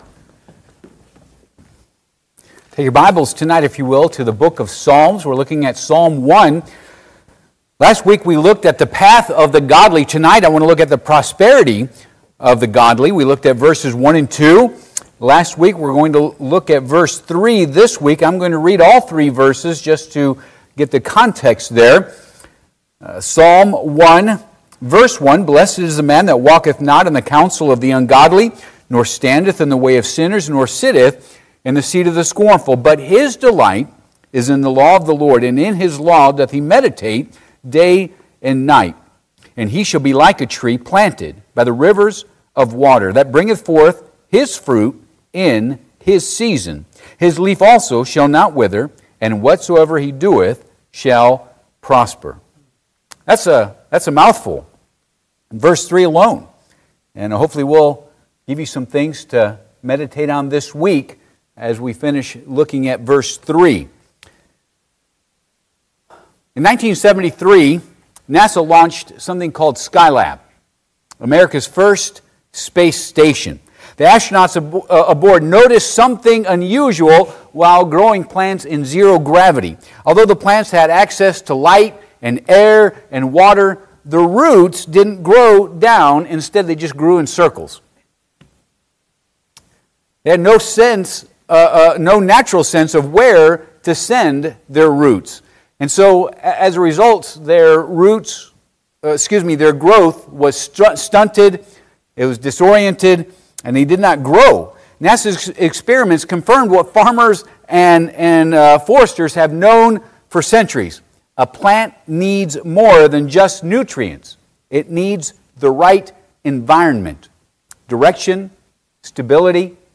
Psalm 1 Passage: Ps. 1:3 Service Type: Midweek Service « A Covenant Worth Keeping The Period & Work of the Judges